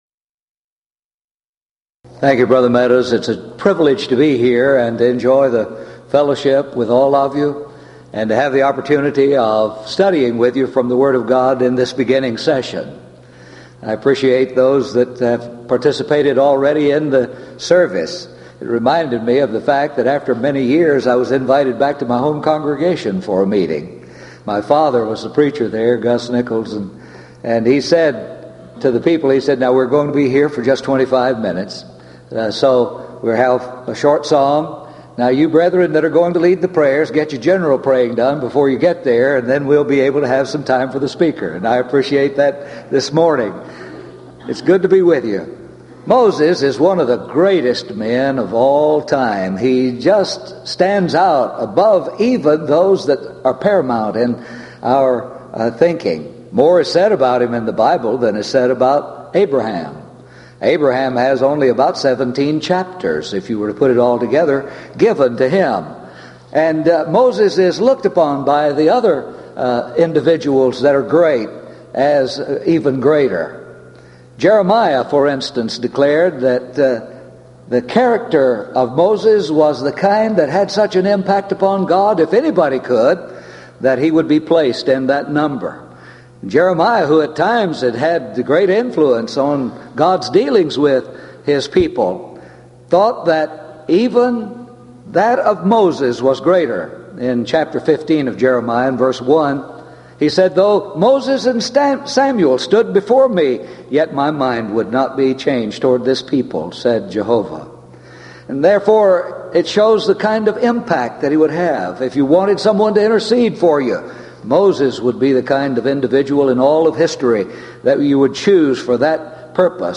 Event: 1997 East Tennessee School of Preaching Lectures Theme/Title: Studies In The Book of Exodus